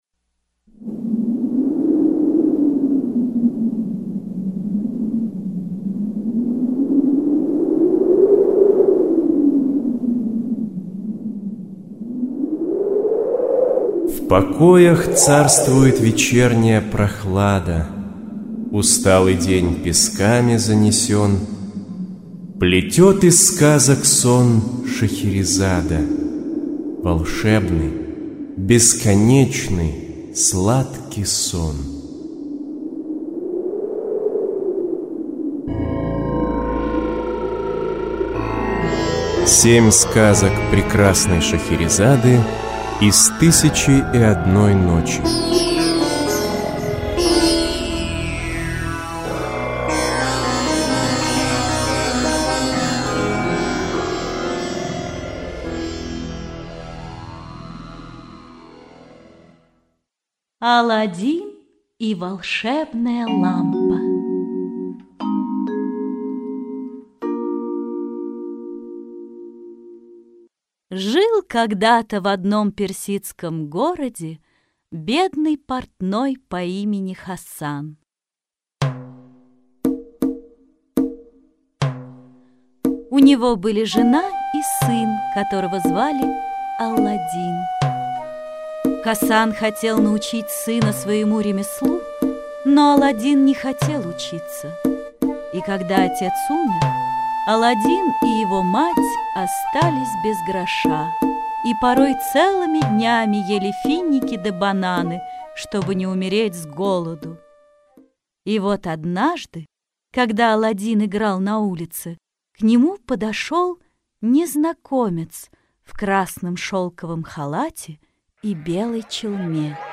Аудиокнига Сказки Тысячи и одной ночи (спектакль) | Библиотека аудиокниг